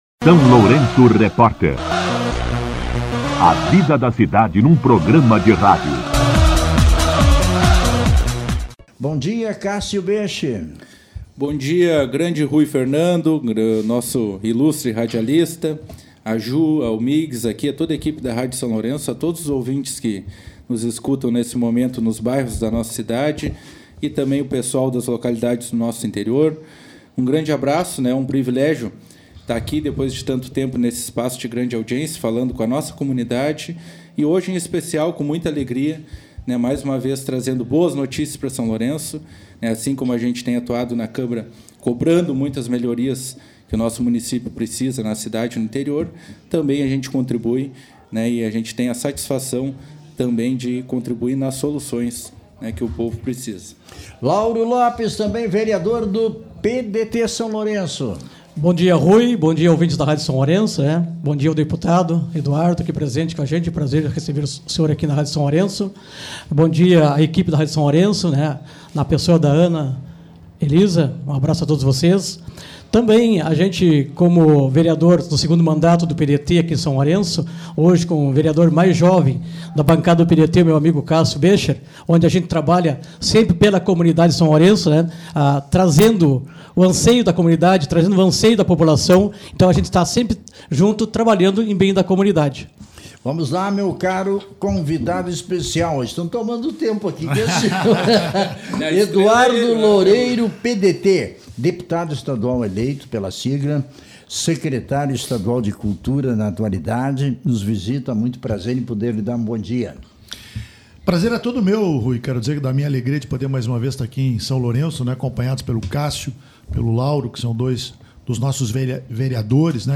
Entrevista com Secretário estadual da Cultura, Eduardo Loureiro e os Vereadores Cássio Boesche e Lauro Lopes
O secretário estadual da Cultura e deputado estadual Eduardo Loureiro (PDT) concedeu entrevista ao SLR RÁDIO na manhã desta terça-feira (27), acompanhado dos vereadores Cássio Boesche e Lauro Lopes. Na conversa, Loureiro anunciou novos investimentos para a área cultural e educacional no município.